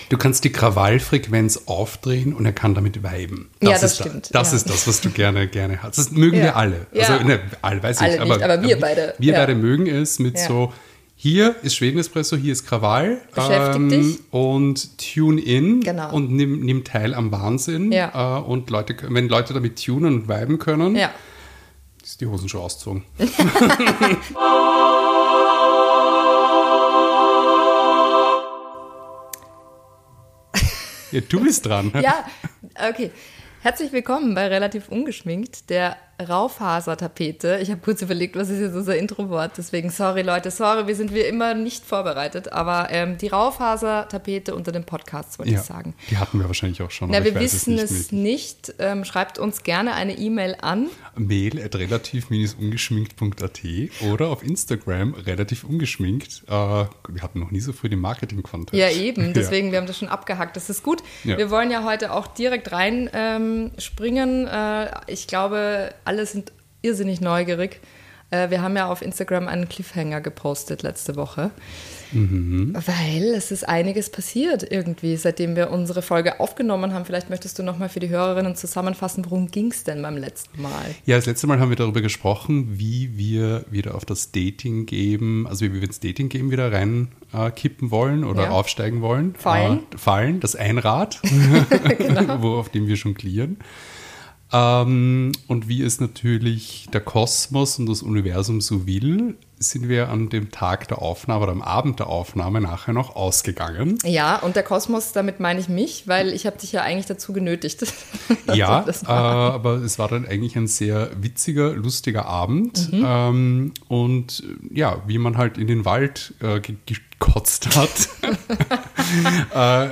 Intro: Sound Effect
Outro: Sound Effect